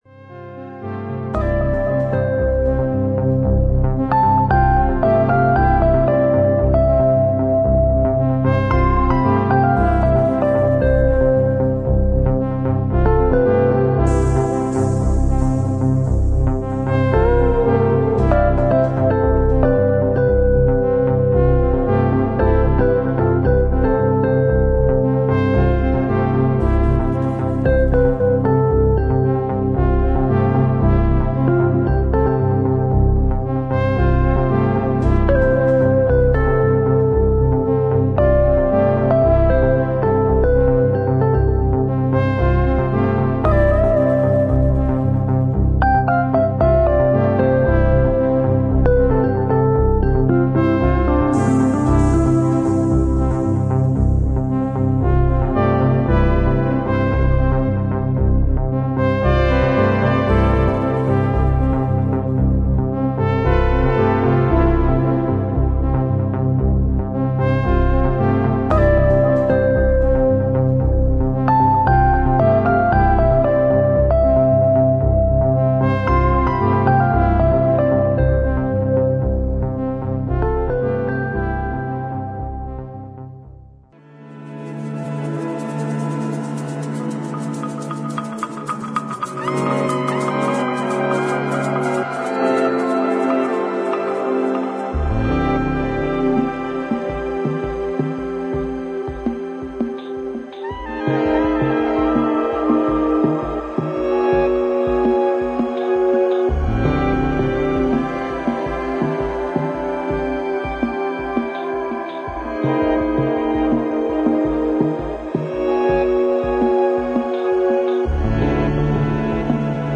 清々しいバレアリック・チューン
穏やかでメディテーティヴな
白昼夢を見ているかの様な心地良さを演出する